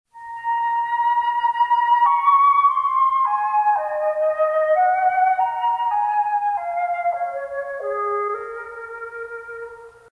Description: Home page intro music